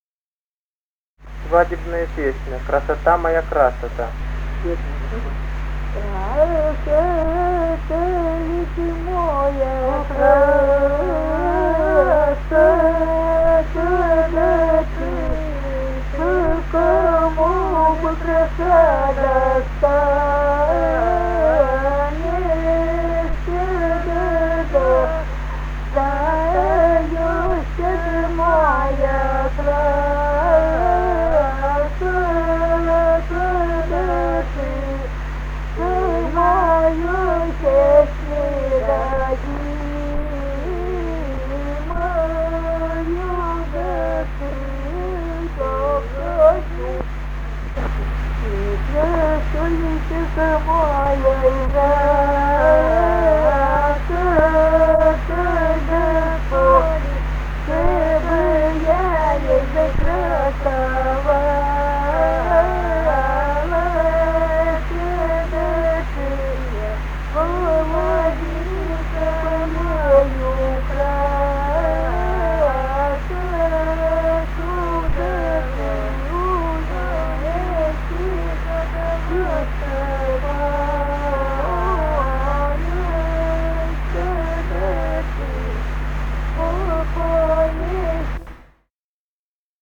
Русские народные песни Красноярского края.
И0284-17 (вариант копии на магнитофонной плёнке).